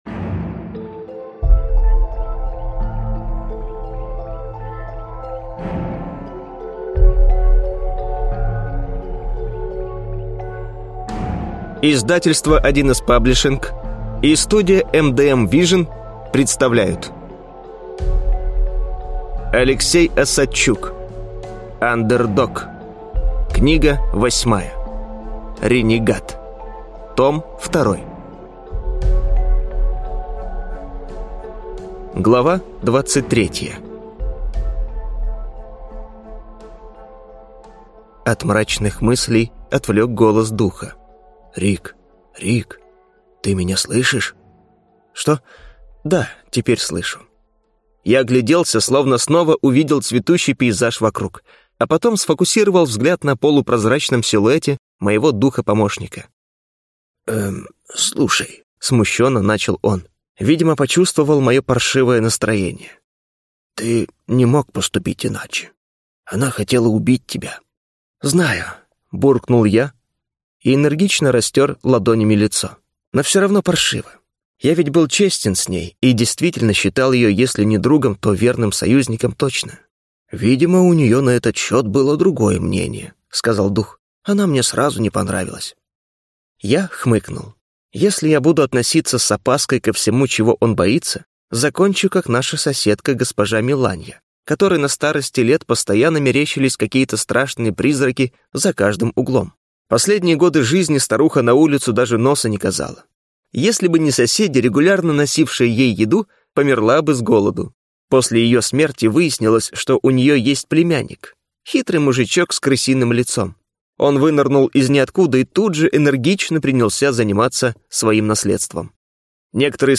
Аудиокнига Ренегат. Том 2 | Библиотека аудиокниг
Прослушать и бесплатно скачать фрагмент аудиокниги